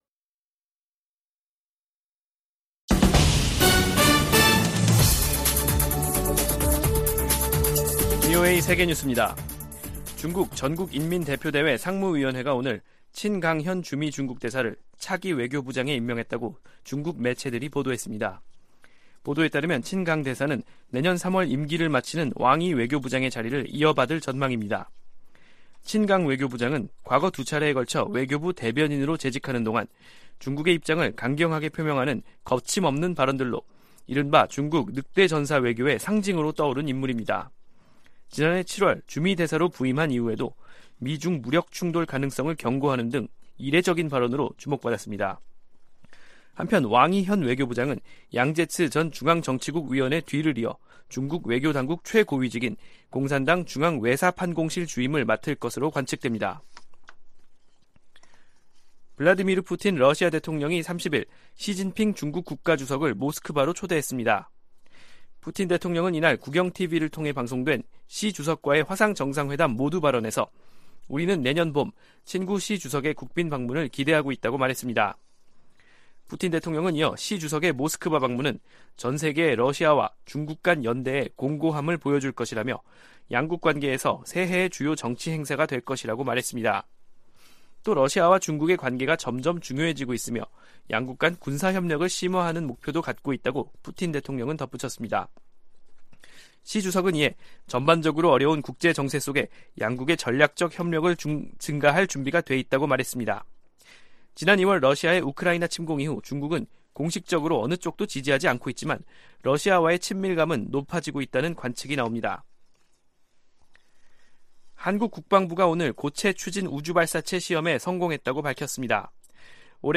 VOA 한국어 간판 뉴스 프로그램 '뉴스 투데이', 2022년 12월 30일 3부 방송입니다. 북한의 무인기 무단 침입으로 9.19 남북 군사합의가 유명무실화하면서 한국 정부는 공세적 대응을 예고하고 있습니다. 미국 국무부가 미북 이산가족 상봉을 위한 노력을 계속하고 있다는 입장을 확인했습니다.